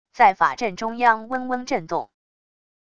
在法阵中央嗡嗡震动wav音频